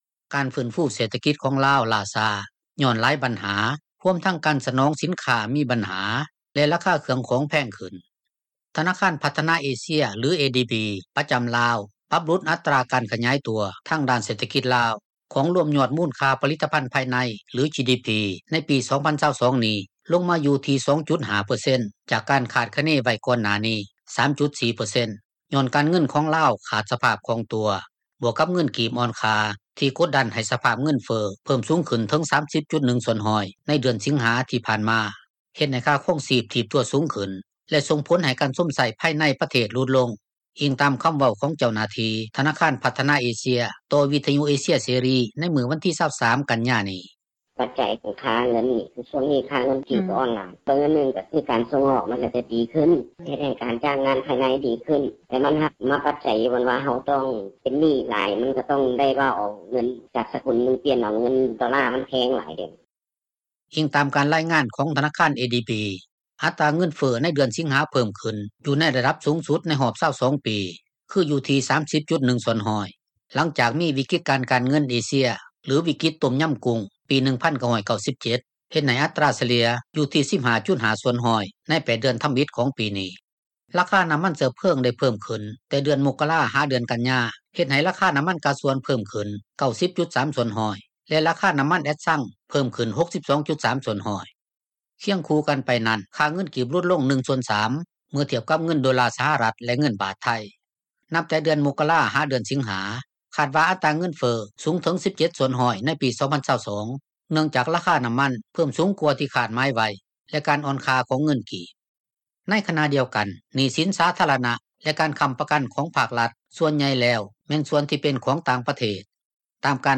ດັ່ງຊາວລາວ ຜູ້ນຶ່ງ ຢູ່ແຂວງອຸດົມໄຊ ເວົ້າໃນມື້ດຽວກັນນີ້ວ່າ: